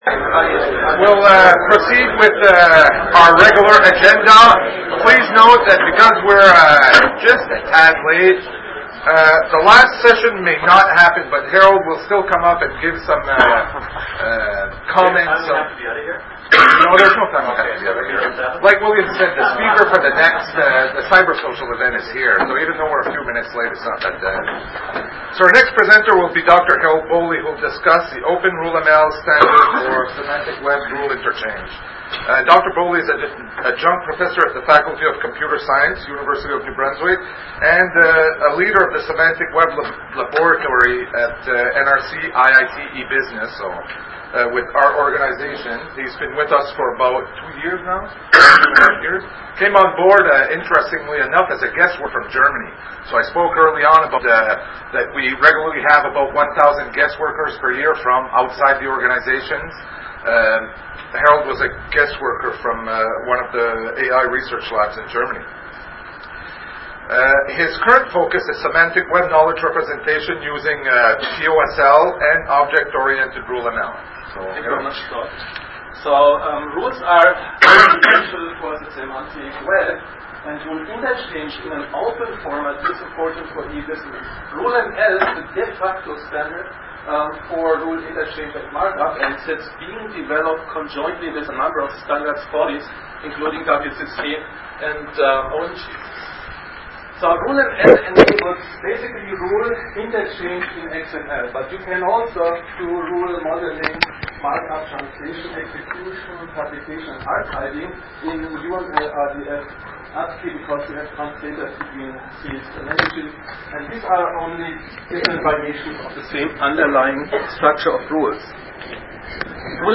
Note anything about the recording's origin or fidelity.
Maritime Open Source Technologies, Moncton Cybersocials, Moncton, New Brunswick, Seminar, Nov 10, 2004.